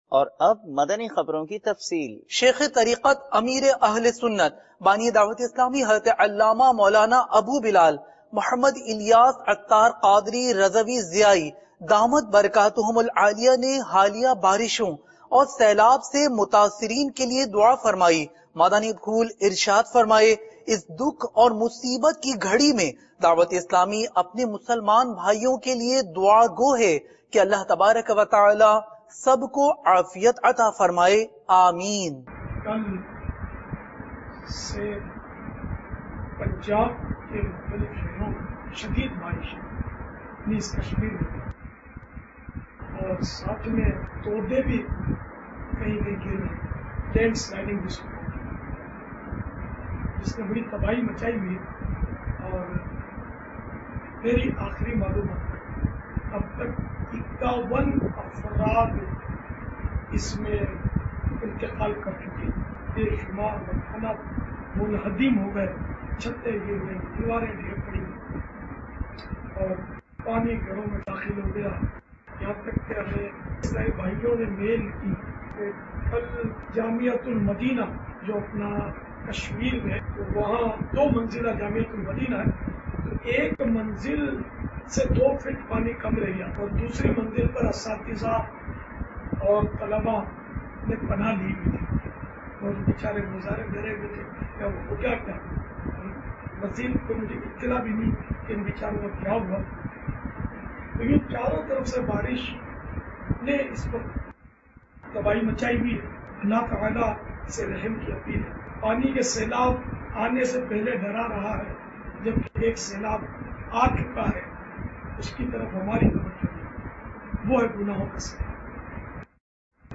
News Clip - 05 Sept - Ameer e Ahl e Sunnat ki Haliya Barshon Kay Mutasreen Say Taziyat Sep 6, 2014 MP3 MP4 MP3 Share نیوز کلپ - 05ستمبر- امیر اہلسنت دامت برکاتہم العالیہ کی حالیہ بارشوں کے متاثرین سے تعزیت